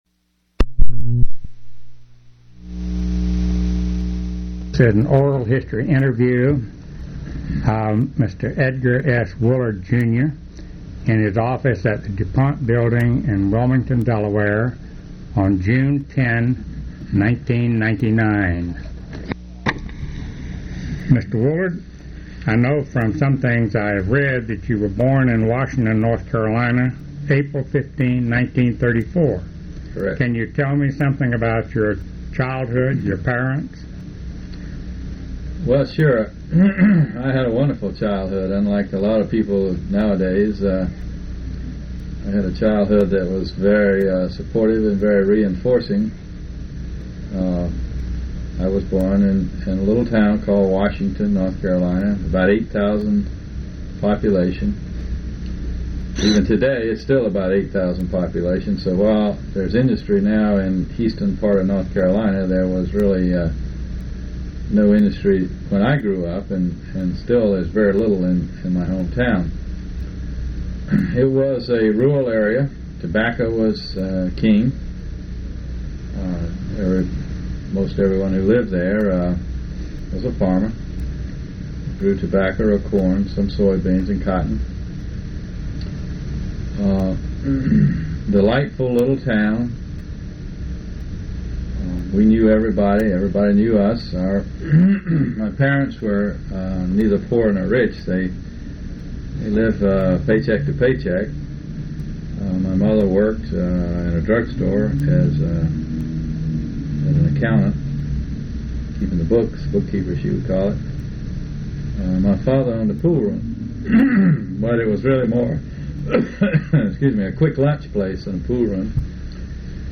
Oral history interview with Edgar S. Woolard Jr.